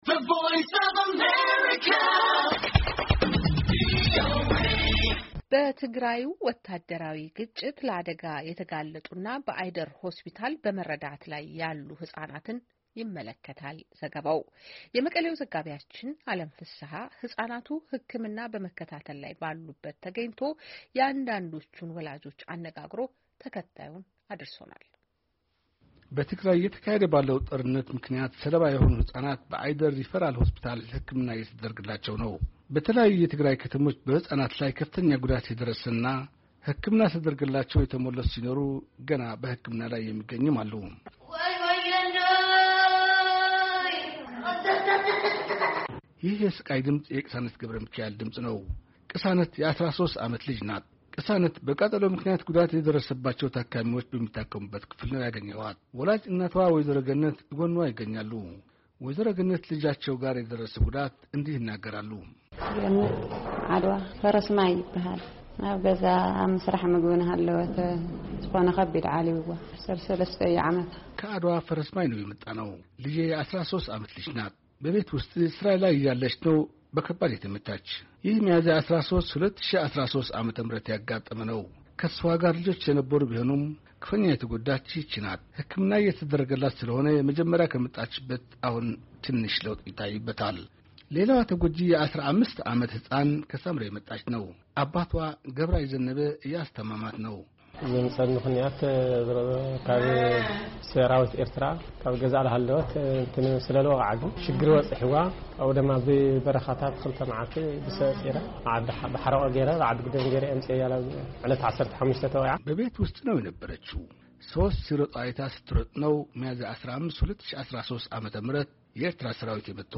መቀሌ —